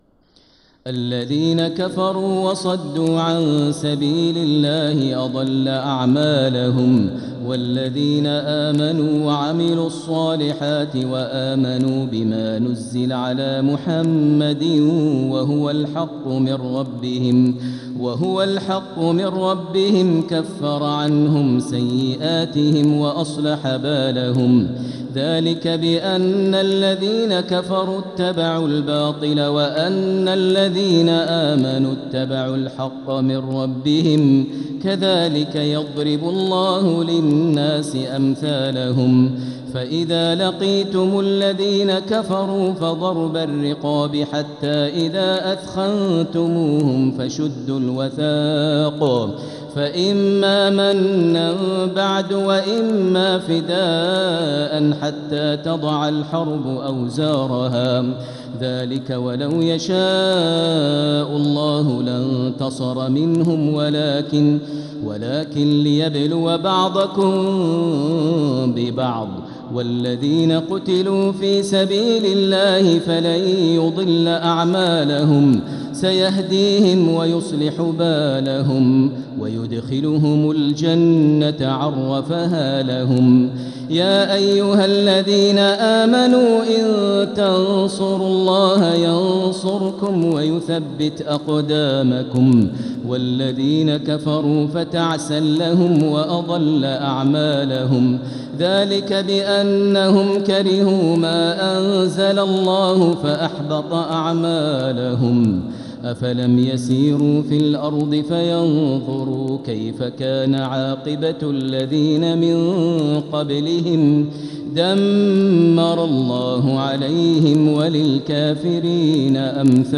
سورة محمد | مصحف تراويح الحرم المكي عام 1446هـ > مصحف تراويح الحرم المكي عام 1446هـ > المصحف - تلاوات الحرمين